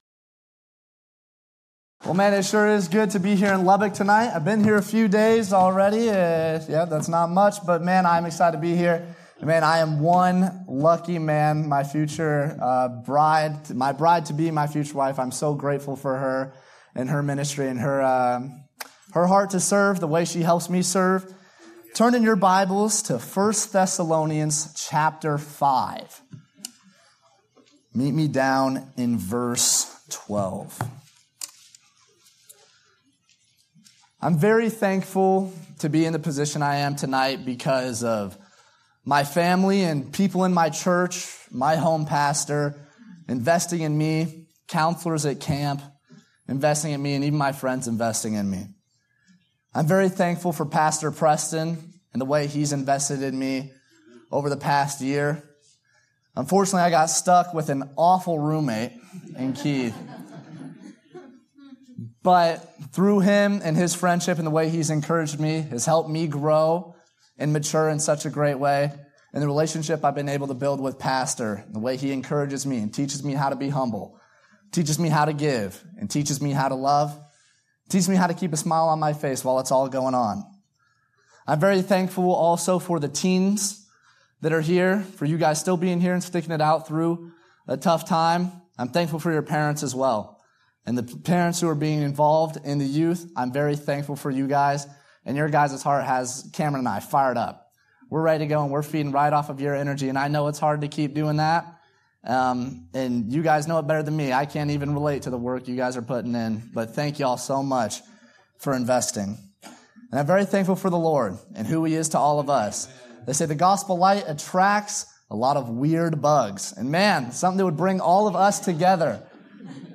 Guest & Staff Preachers